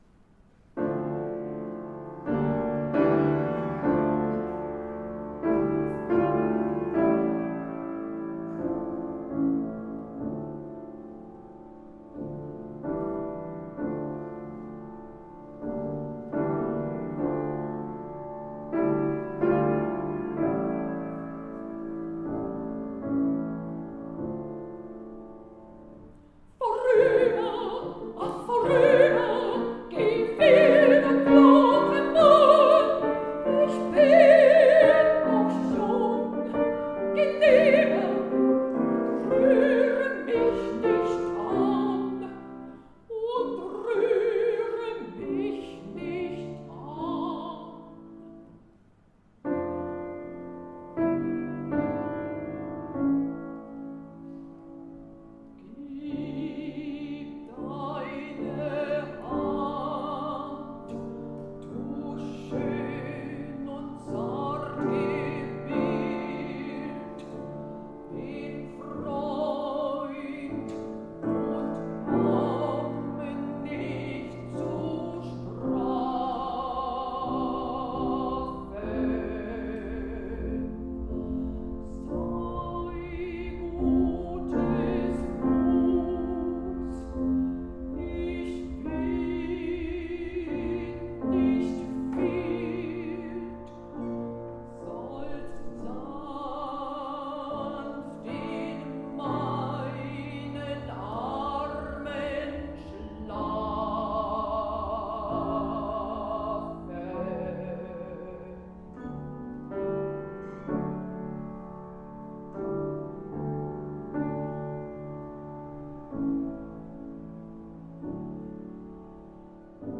Konzert am 25.06.2023 im Gemeindesaal der 12-Apostel-Kirche in Schöneberg. Am Klavier